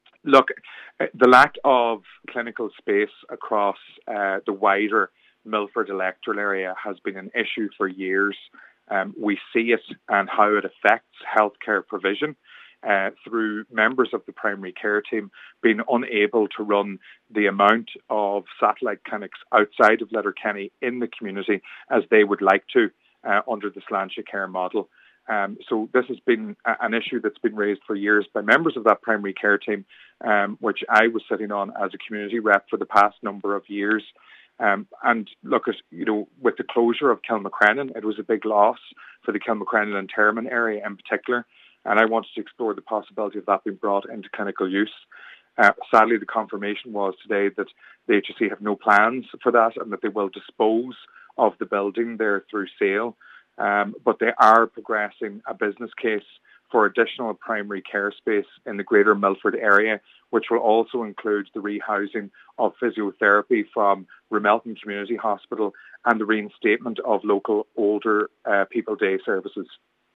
Cllr Meehan says the current lack of space affects healthcare provision, with care teams unable to perform to capacity: